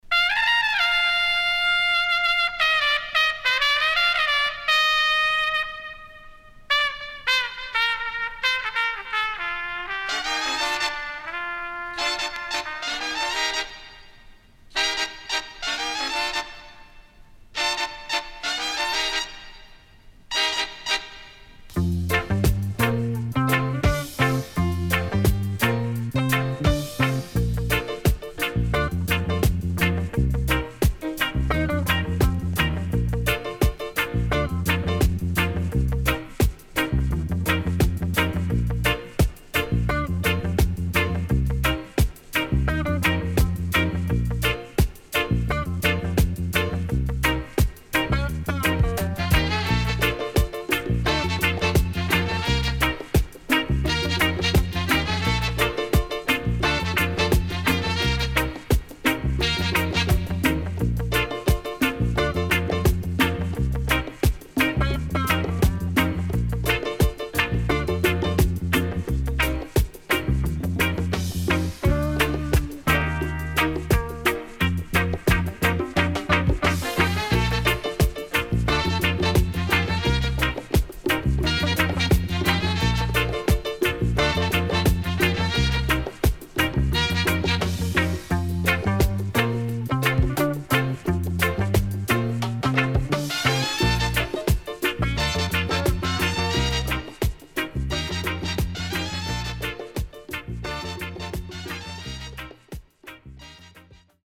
SIDE A:うすいこまかい傷ありますがノイズあまり目立ちません。